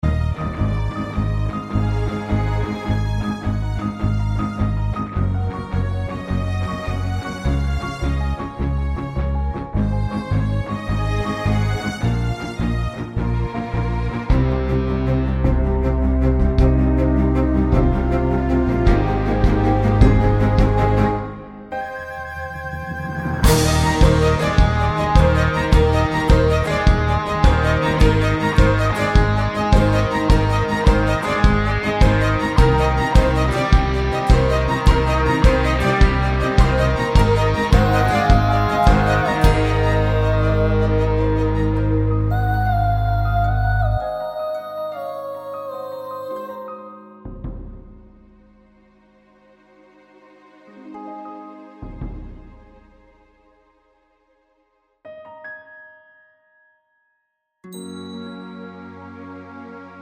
no Backing Vocals Soundtracks 3:14 Buy £1.50